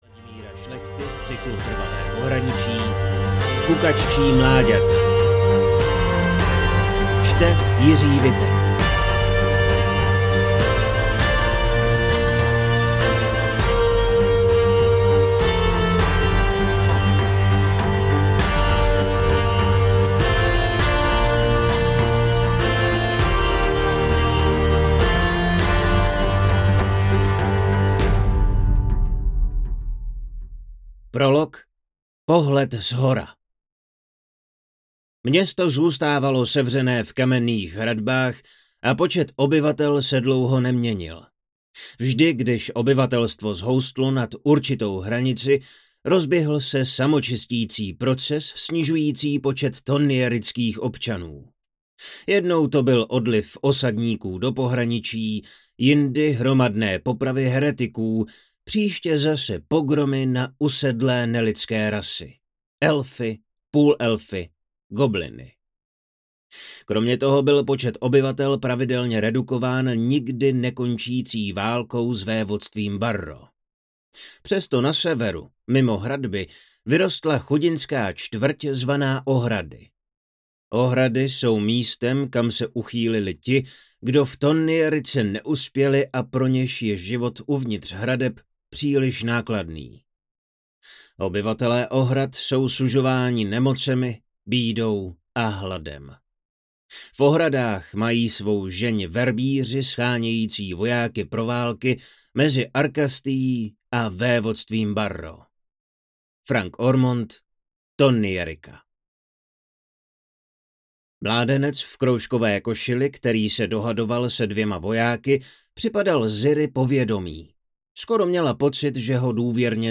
Kukaččí mláďata audiokniha
Ukázka z knihy